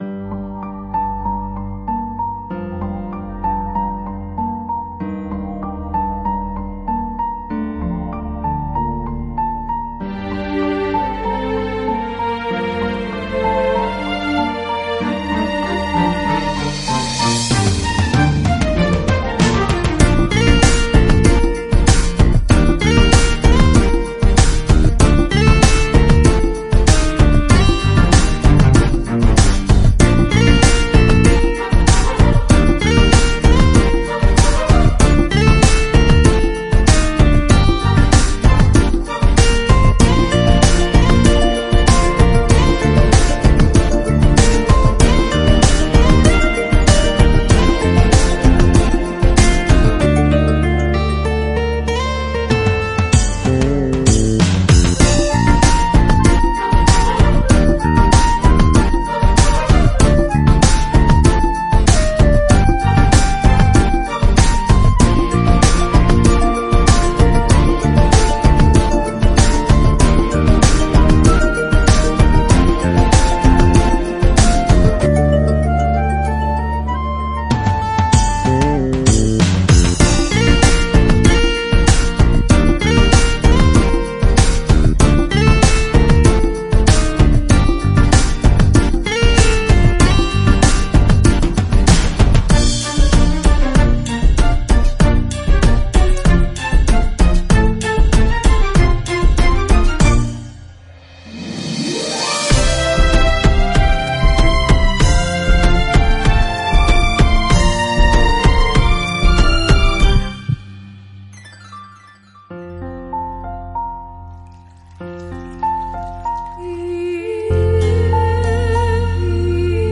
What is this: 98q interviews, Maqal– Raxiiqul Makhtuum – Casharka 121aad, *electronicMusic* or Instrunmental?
Instrunmental